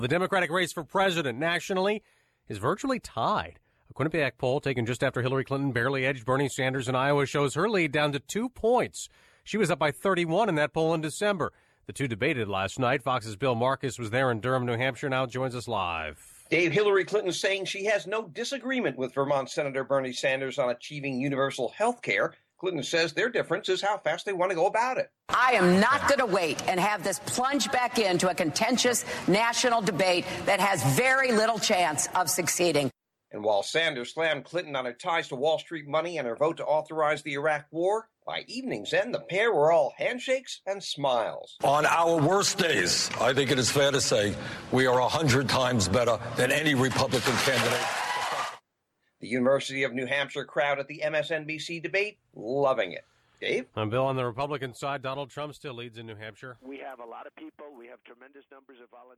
(MANCHESTER, NH) FEB 5- 9AM LIVE –